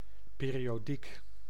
Ääntäminen
US : IPA : [ˌpɪr.i.ˈɑː.dɪ.kəl]